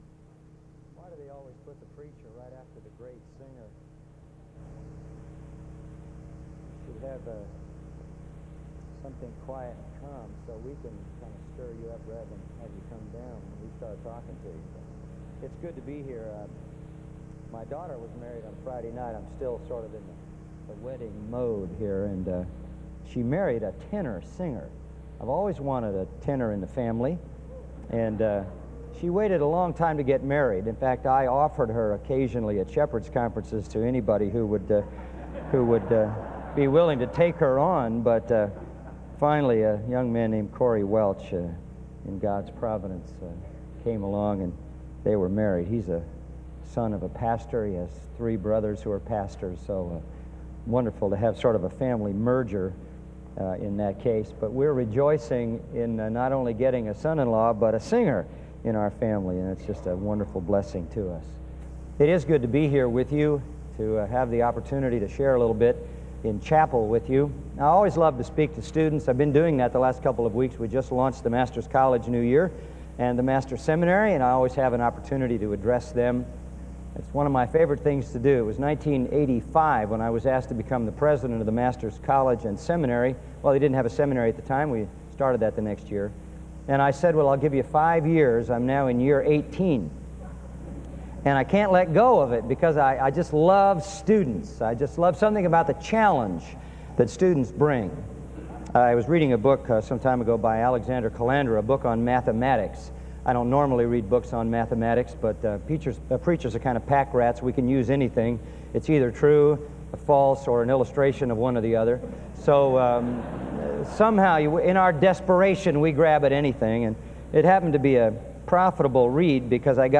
SEBTS_Chapel_John_MacArthur_2003-09-02.wav